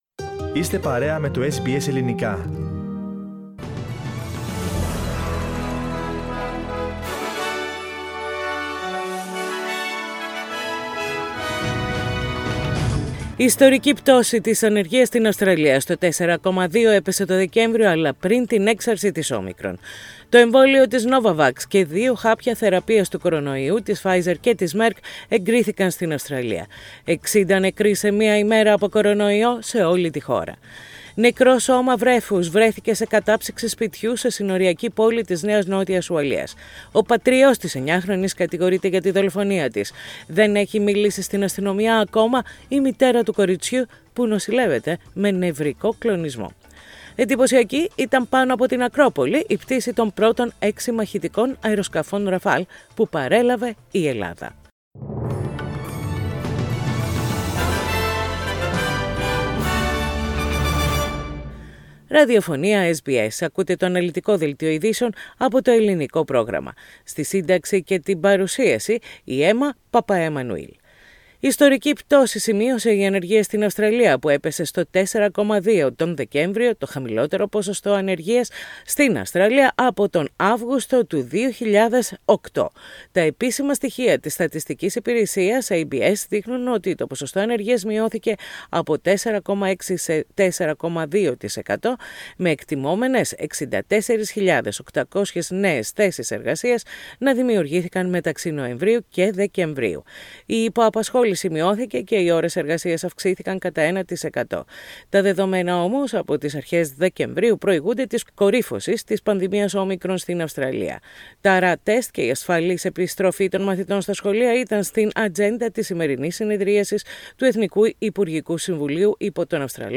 Ειδήσεις στα Ελληνικά - Πέμπτη 20.1.22
News in Greek. Source: SBS Radio